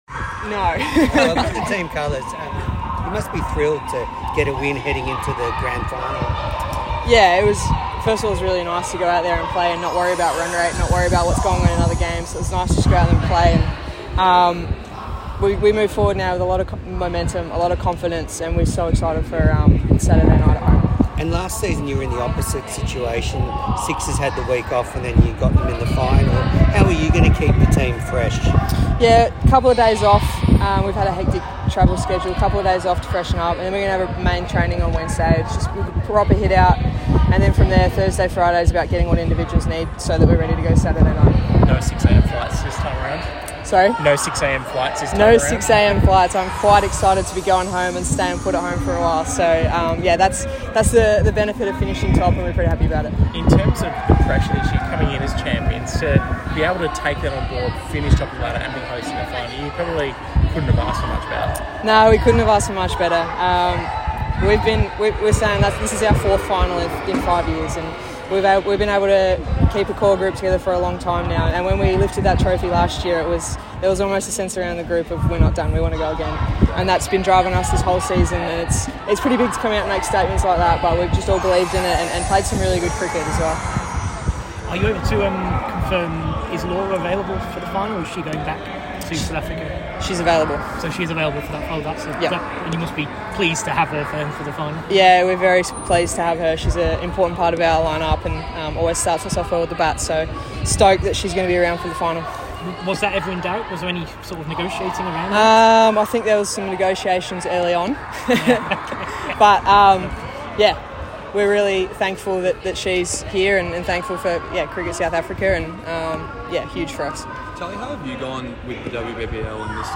Strikers captain and POTM Tahlia McGrath post-match interview after 77-run win v Hurricanes at SCG